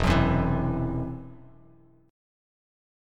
Esus2#5 chord